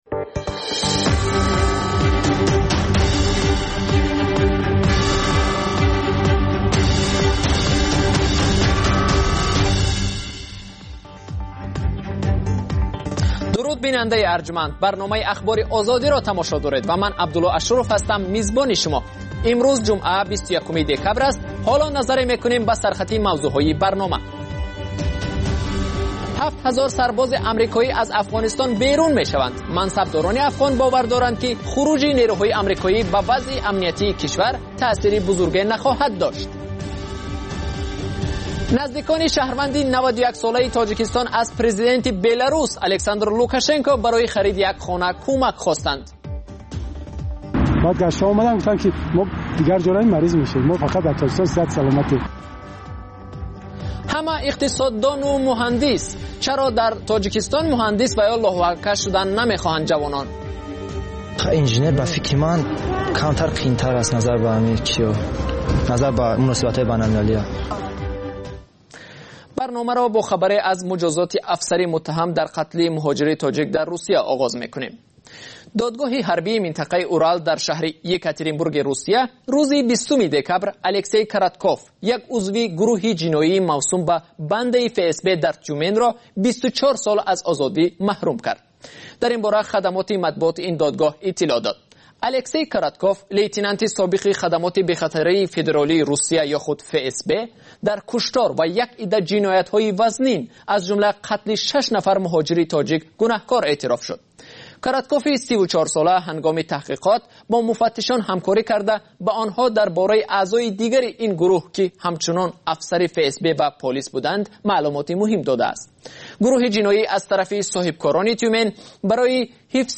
Тозатарин ахбор ва гузоришҳои марбут ба Тоҷикистон, минтақа ва ҷаҳон дар маҷаллаи бомдодии Радиои Озодӣ